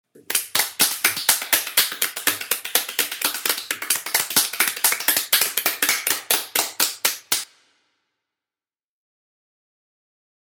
Clapping